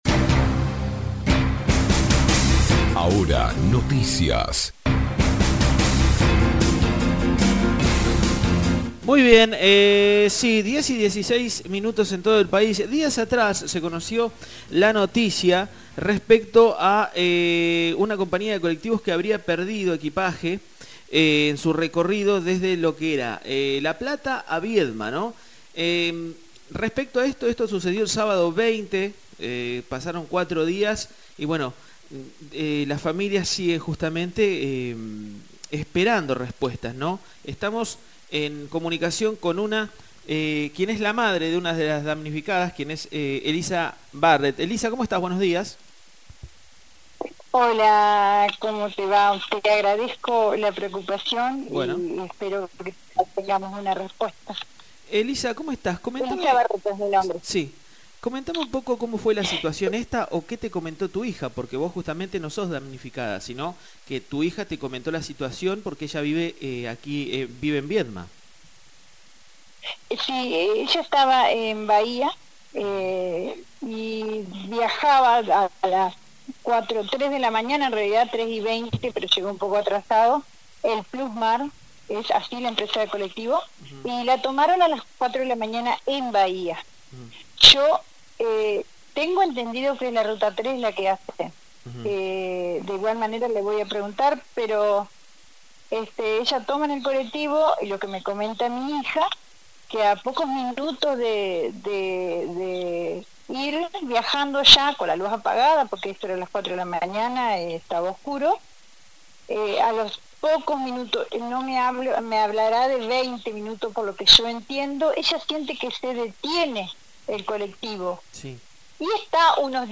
En diálogo con nuestro medio